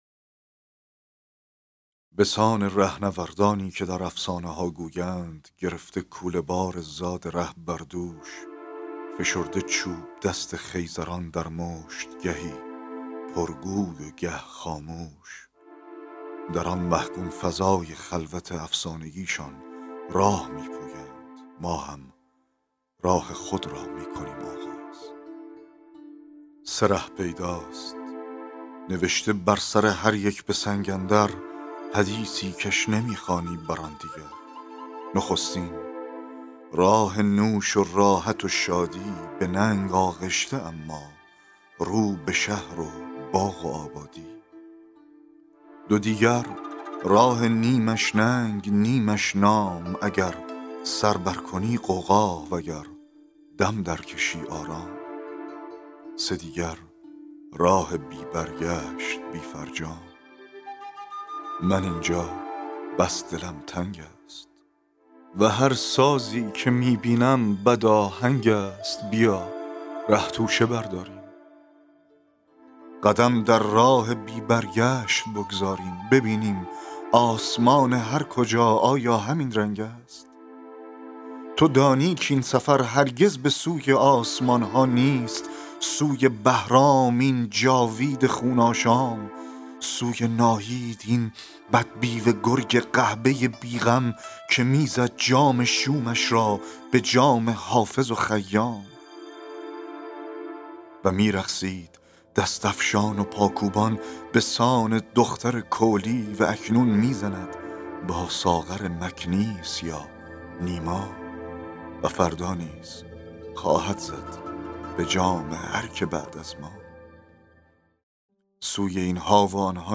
فایل صوتی دکلمه شعر چاووشی